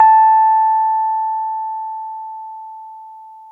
RHODES CL0HL.wav